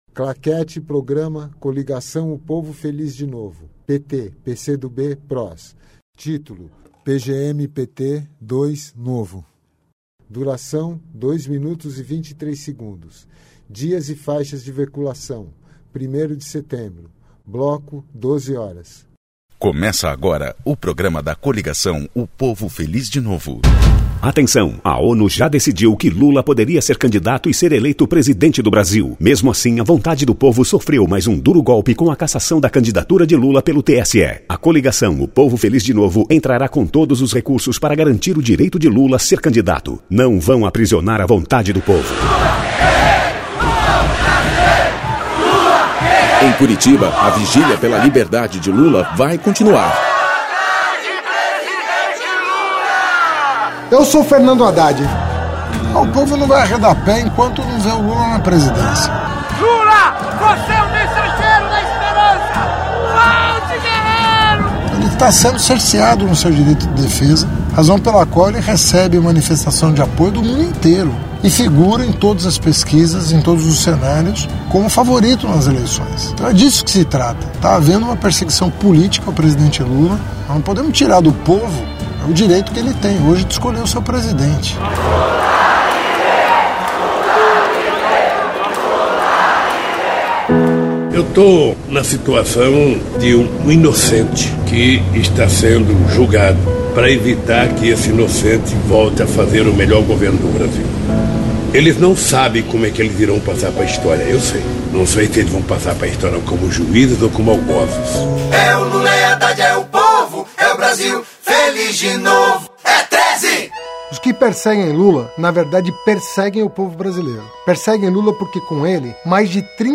TítuloPrograma de rádio da campanha de 2018 (edição 02)
Descrição Programa de rádio da campanha de 2018 (edição 02) - 1° turno